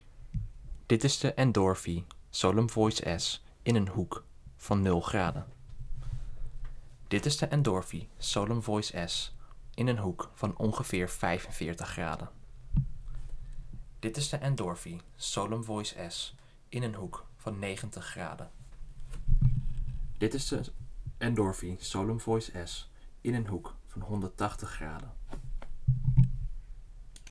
De opnames klinken natuurgetrouw en er is geen sprake van gekraak, gezoem, of overmatig achtergrondgeluid.
Endorfy Solum Voice S - Off-axis hoekentest
Solum-Voice-S-graden.m4a